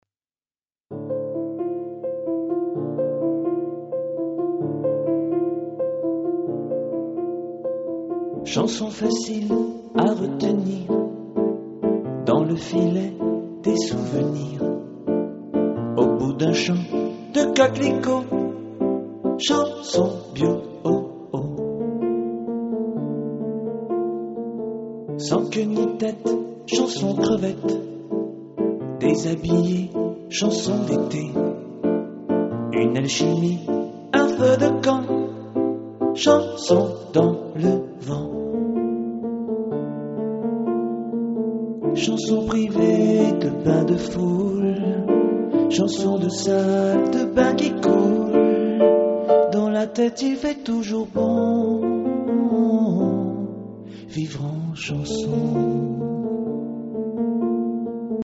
chansonnier